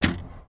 bow.wav